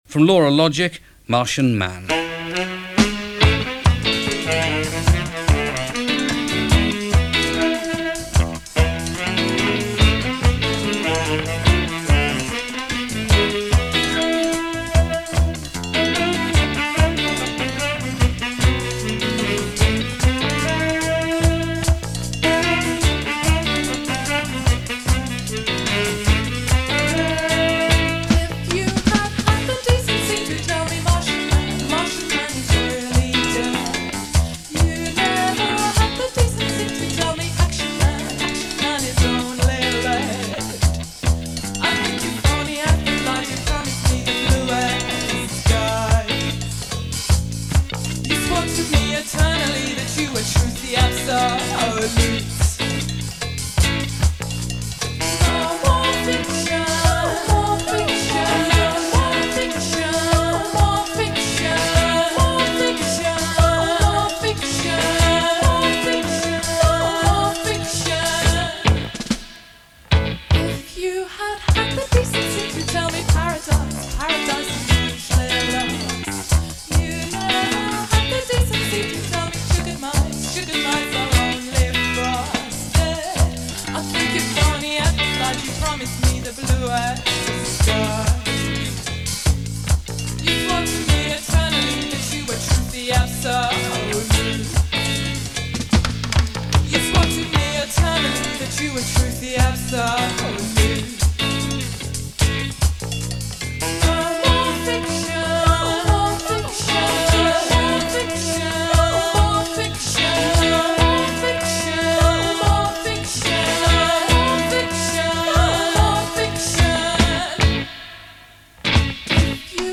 unsung pivotal figures in Punk and New Wave.
Her unique and distinctive sax playing